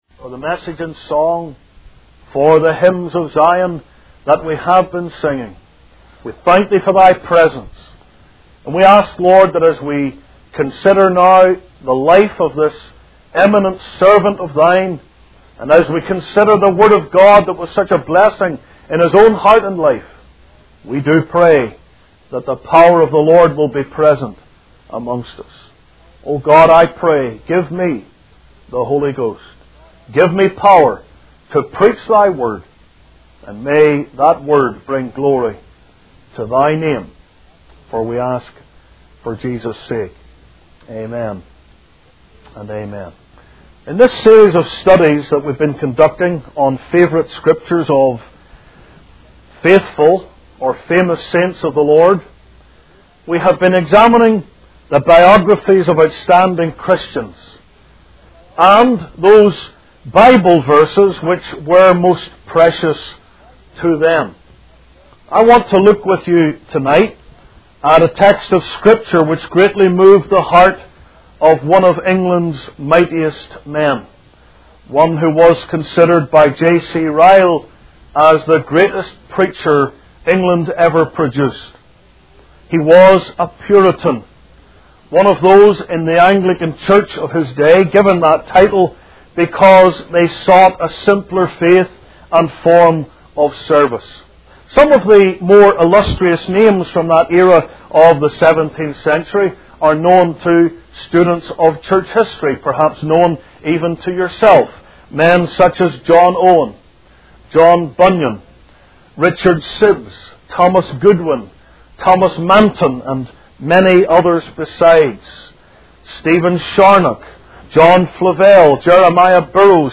In this sermon, the preacher discusses the state of the church and the need for repentance.